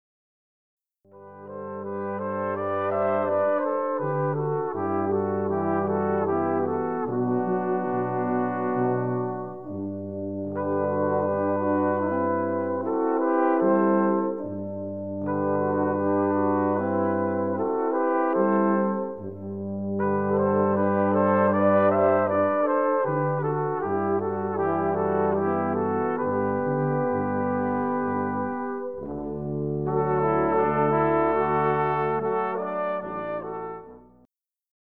For Brass Quintet